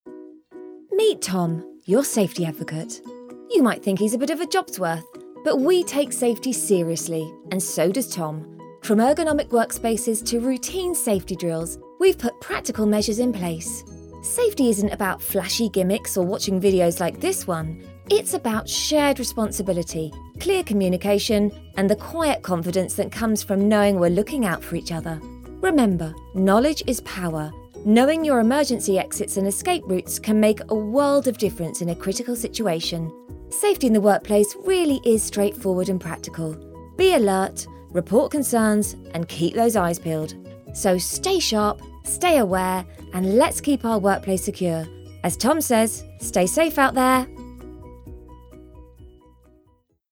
A lively British female voice
Explainer video
British general
Middle Aged